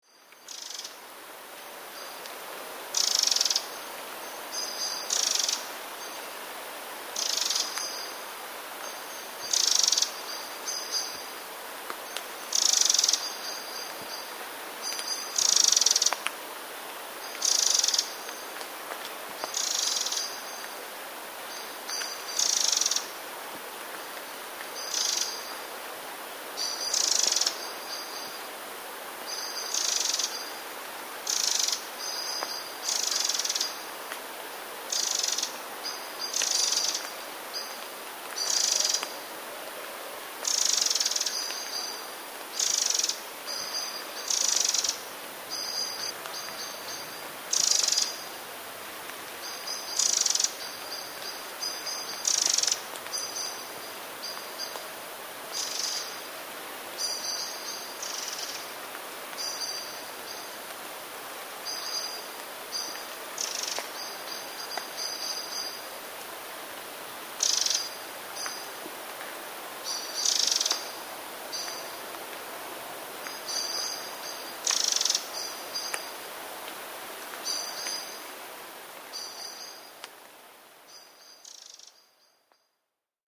terkoty strzyżyka, ale nie wiem, co one oznaczają.
78strzyzyk.mp3